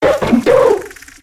infinitefusion-e18/Audio/SE/Cries/TRUBBISH.ogg at a50151c4af7b086115dea36392b4bdbb65a07231